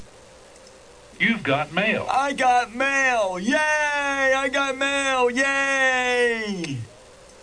igotmailyeh.mp3